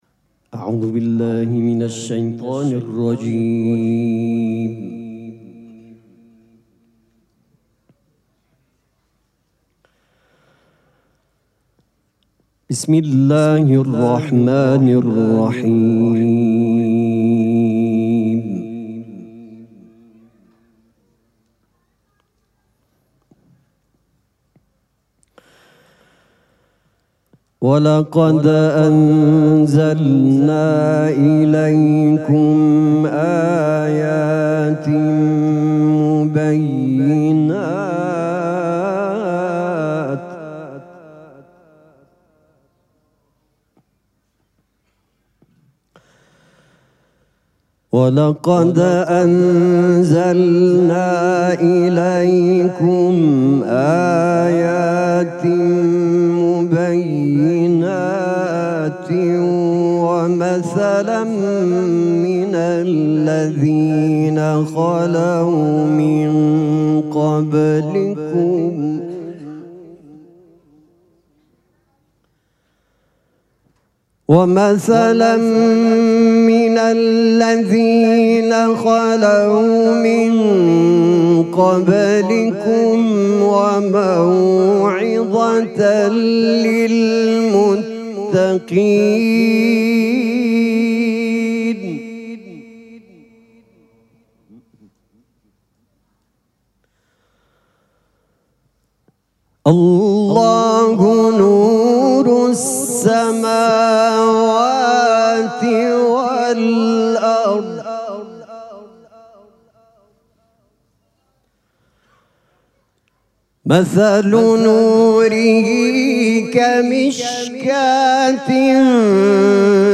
مراسم جشن ولادت حضرت زهرا سلام الله علیها
قرائت قرآن